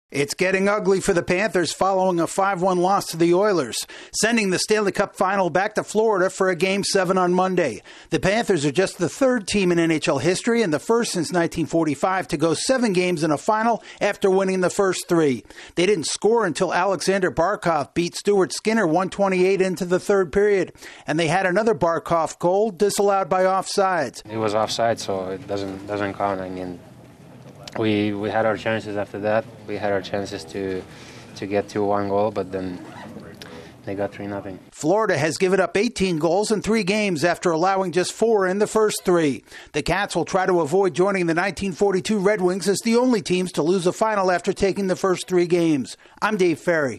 The Panthers are staring down the wrong side of history. AP correspondent